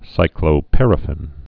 (sīklō-părə-fĭn)